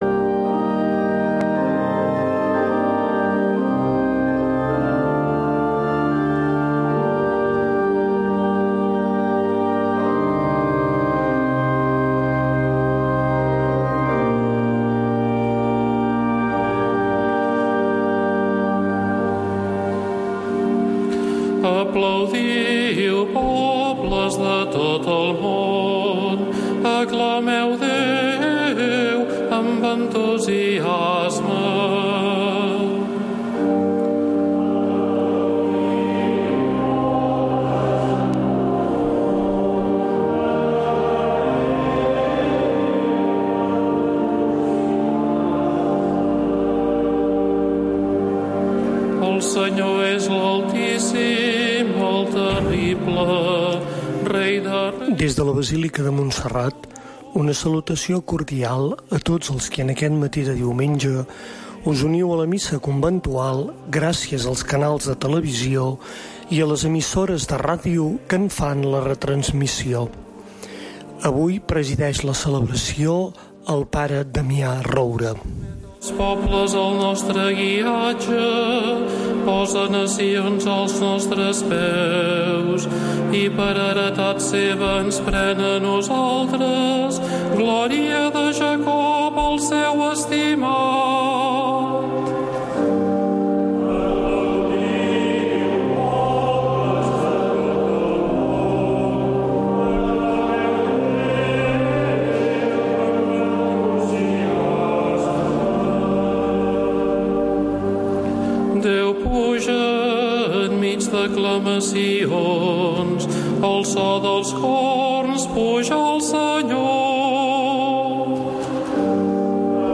Retransmissió en directe de la missa conventual des de la basílica de Santa Maria de Montserrat.